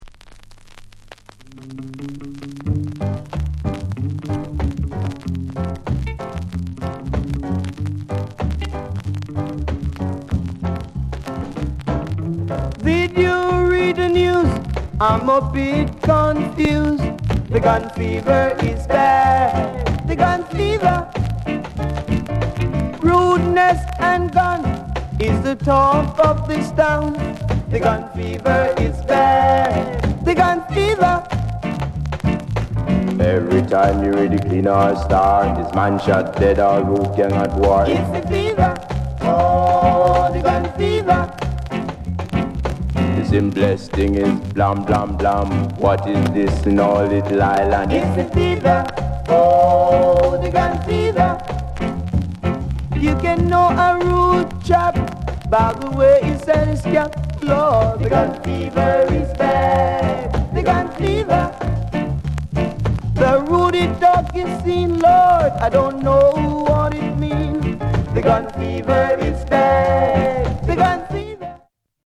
SOUND CONDITION A SIDE VG(OK)
RARE ROCKSTEADY